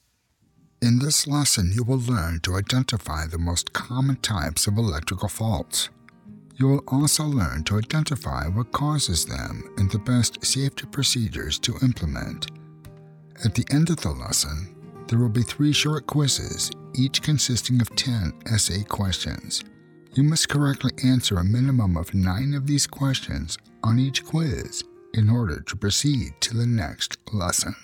eLearning- Engaging, Professional, Captivating, Deep, Warm, Genuine, Articulate, Sincere, Friendly
North American Midwest, Upper Midwest
eLearning Electrical Faults_mixdown.mp3